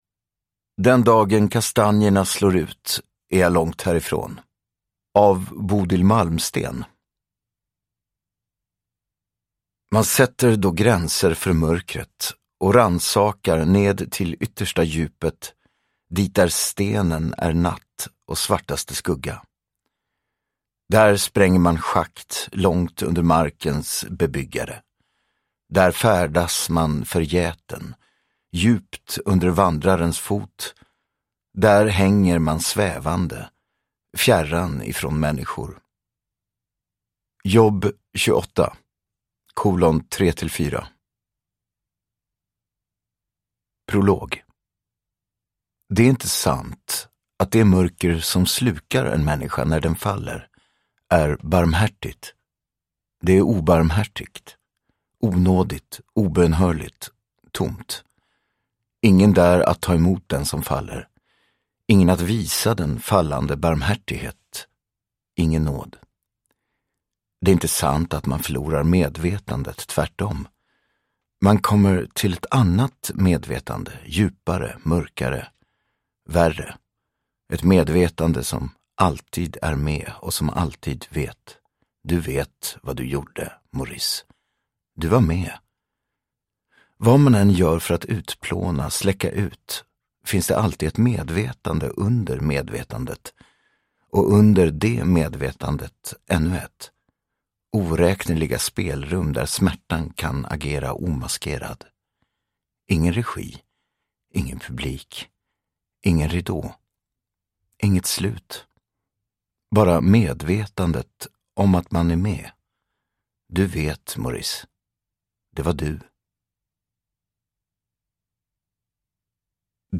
Uppläsare: Gerhard Hoberstorfer
Ljudbok